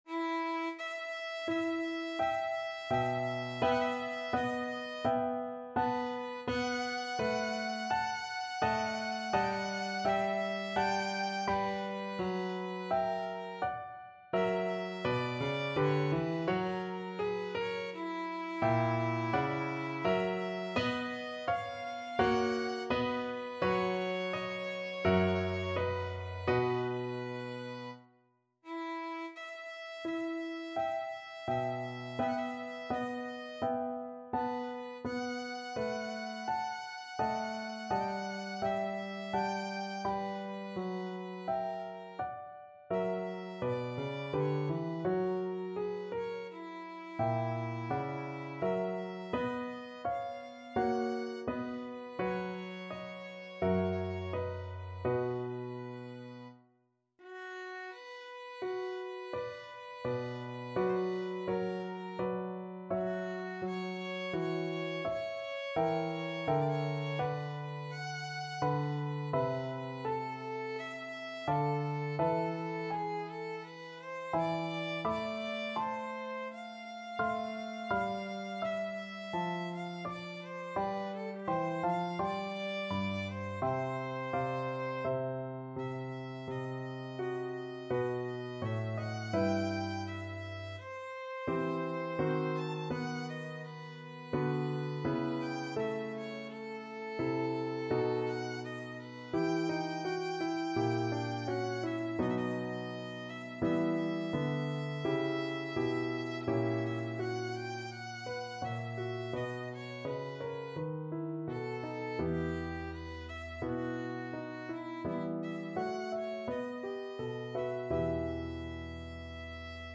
4/4 (View more 4/4 Music)
E5-A6
Largo =42